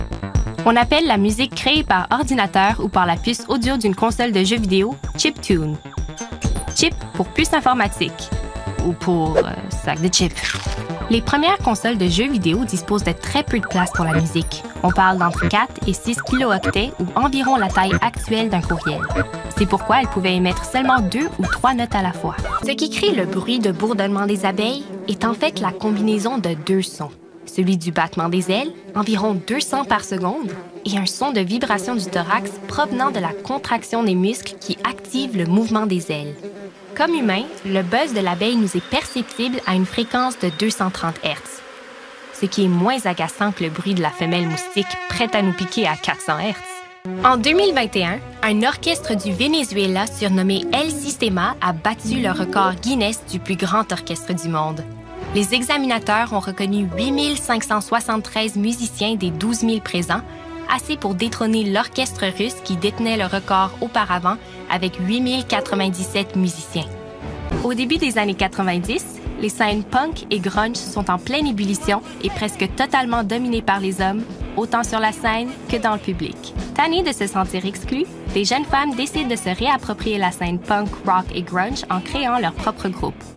Narration - FR